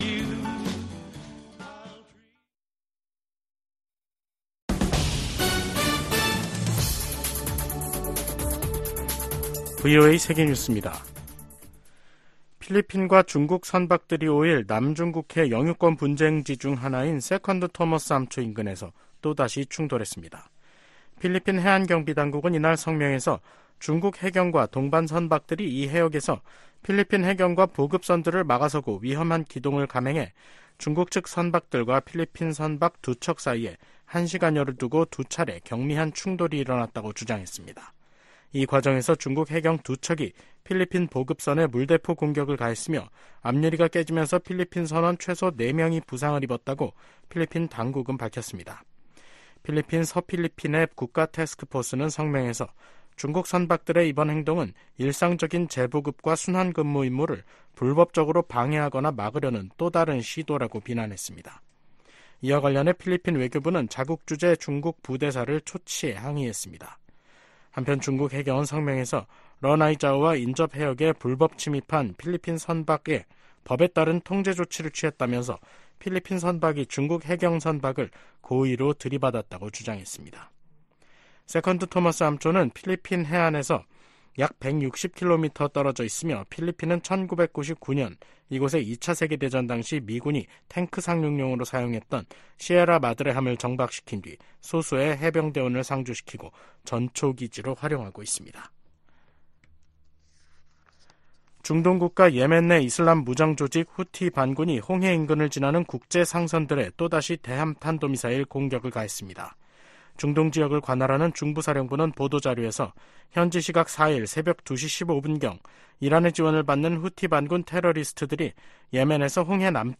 VOA 한국어 간판 뉴스 프로그램 '뉴스 투데이', 2024년 3월 5일 3부 방송입니다. 북한의 영변 경수로 가동 움직임이 계속 포착되고 있다고 국제원자력기구(IAEA)가 밝혔습니다. 북한에서 철수했던 유럽 국가들의 평양 공관 재가동 움직임에 미국 정부가 환영의 뜻을 밝혔습니다. 북한은 4일 시작된 미한 연합훈련 '프리덤실드(FS)'가 전쟁연습이라고 주장하며 응분의 대가를 치를 것이라고 위협했습니다.